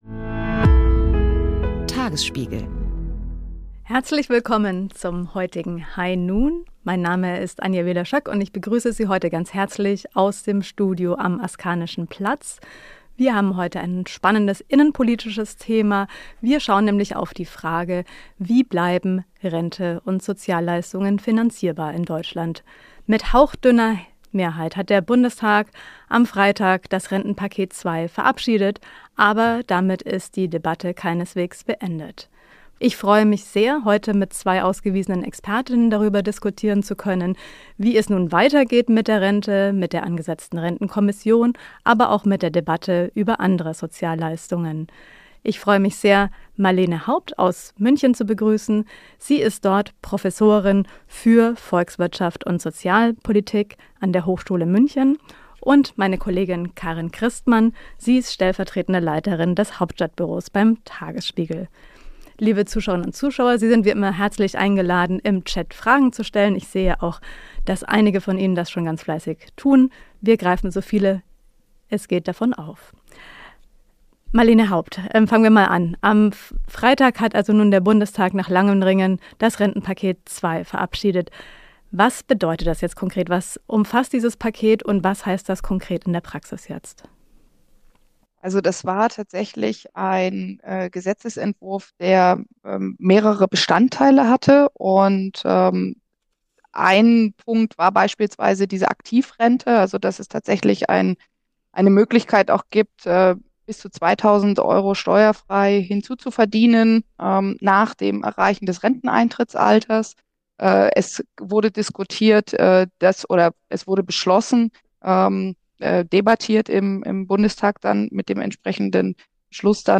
Was das konkret bedeutet und wie es nun weitergeht, analysieren die Tagesspiegel-Experten im Live-Talk.